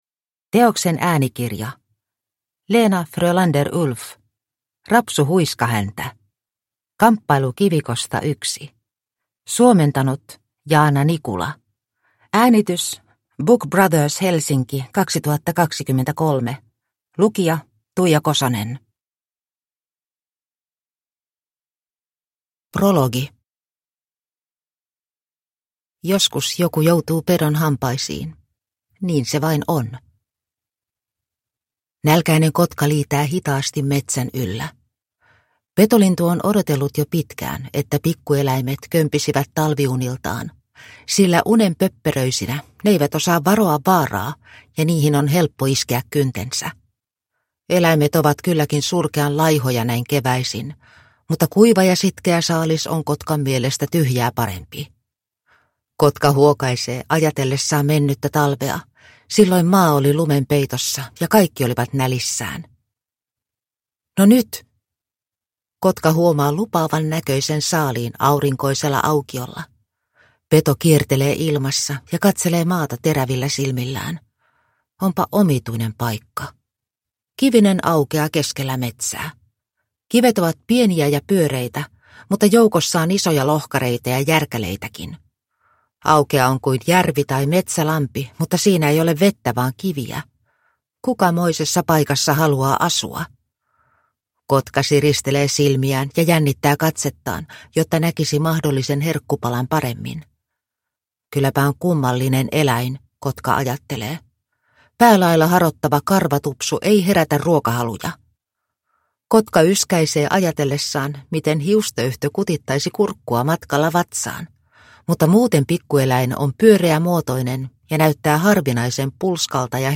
Rapsu Huiskahäntä – Ljudbok